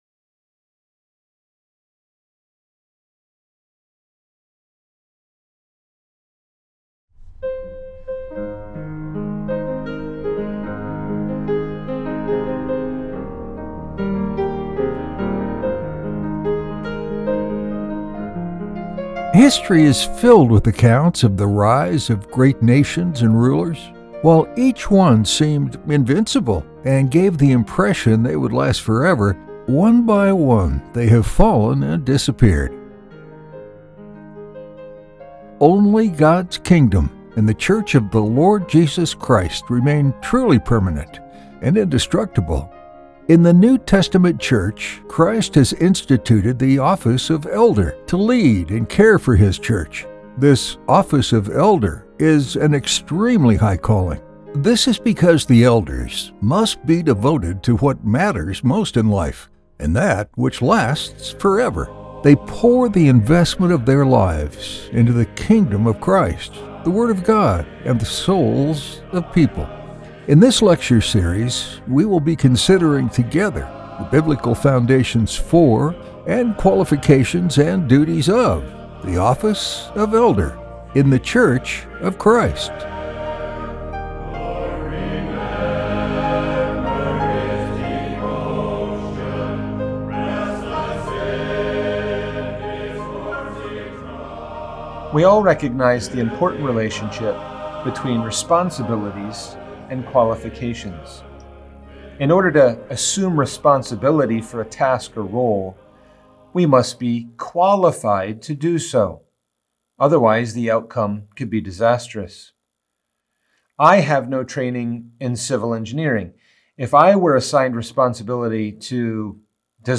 So now that we understand the biblical qualifications for the office of Elder in Christ’s church, we need to explore the broader context of the qualifications, and some specific details. In this lecture, we will consider the important of knowing a man’s abilities and graces, his character, his life, his godliness, his maturity, and even his experience.